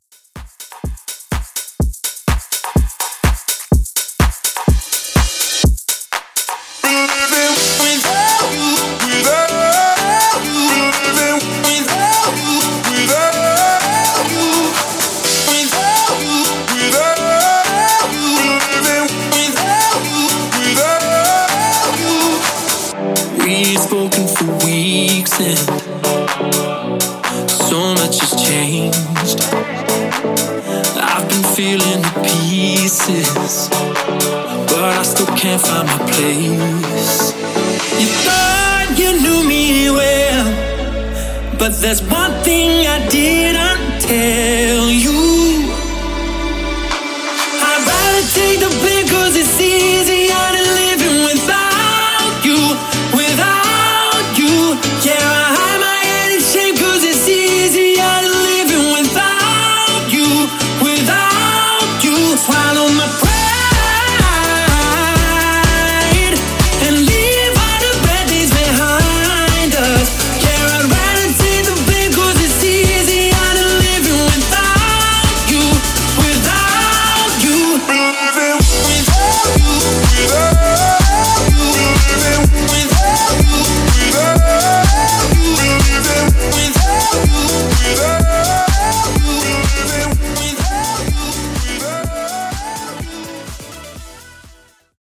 Club Redrum)Date Added